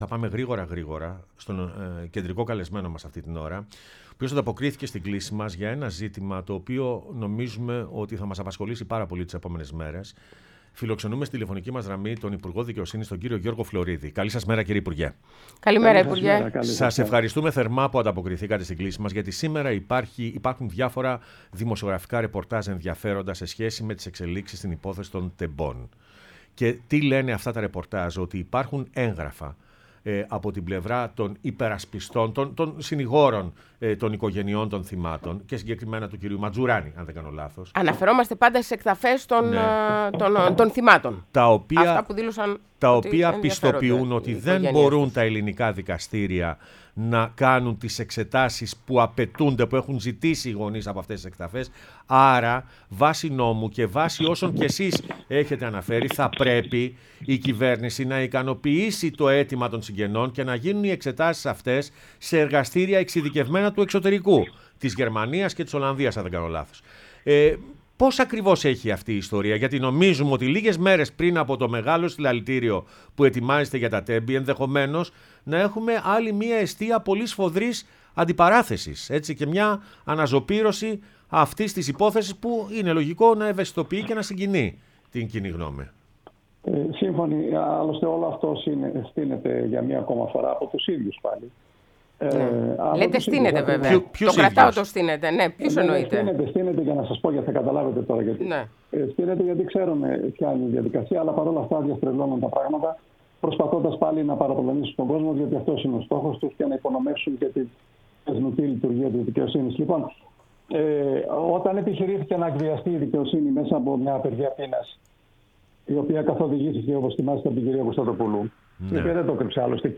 Ο Γιώργος Φλωρίδης στο ΕΡΤnews Radio 105,8 | 25.02.2026